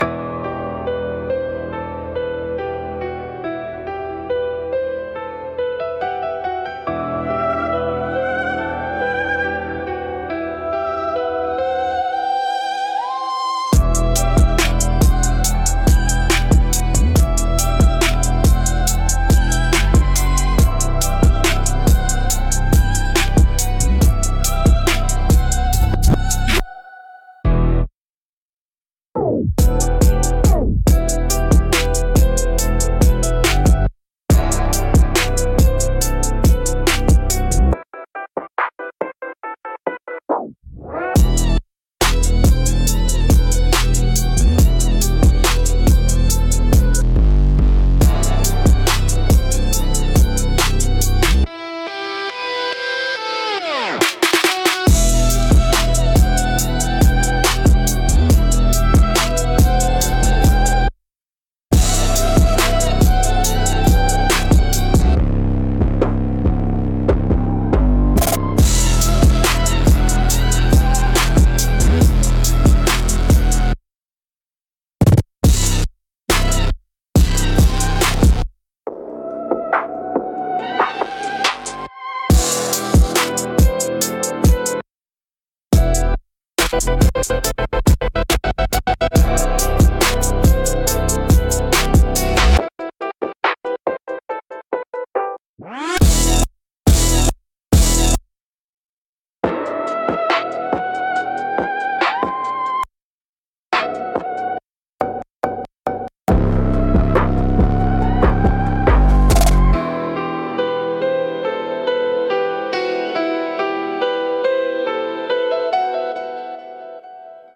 Instrumentals - Weight of the Block